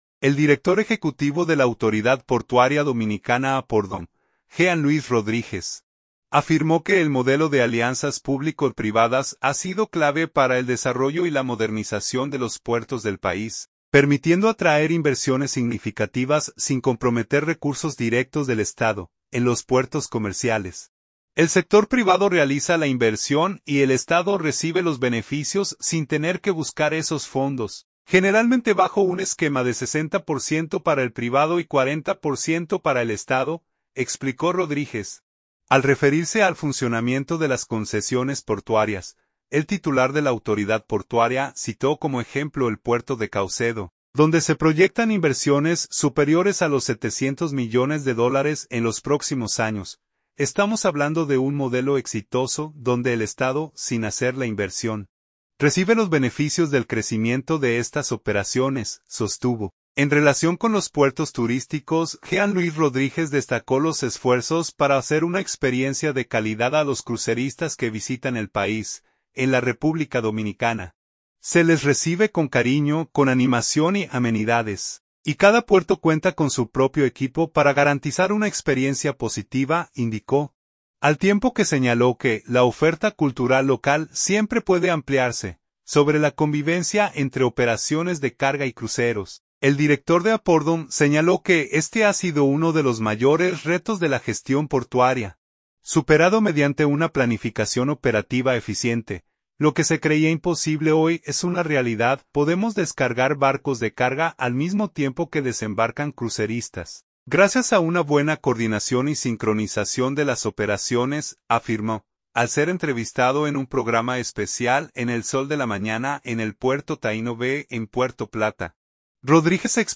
Al ser entrevistado en un programa especial en el Sol de la Mañana en el puerto Taíno Bay en Puerto Plata, Rodríguez explicó que, para proteger la experiencia de los visitantes y el entorno, se reubican temporalmente operaciones que generan polvo o ruido y se realizan dragados y batimetrías permanentes.